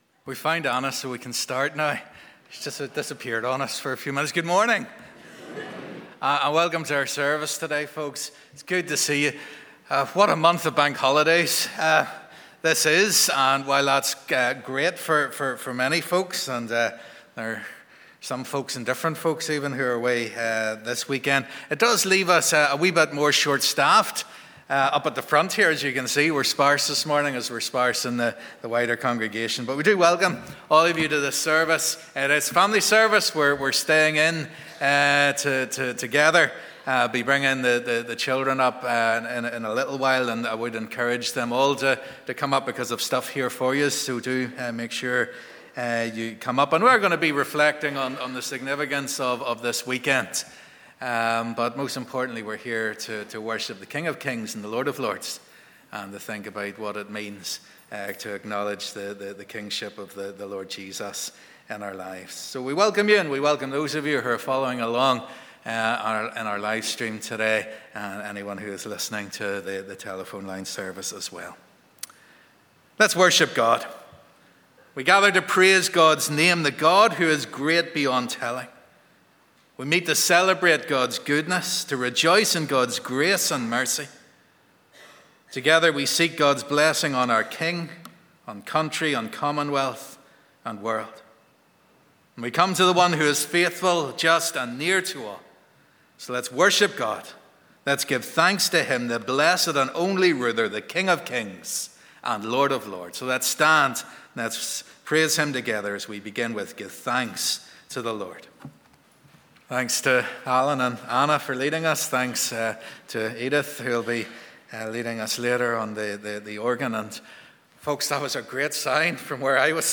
The theme for this family service is 'Who Will Be King?'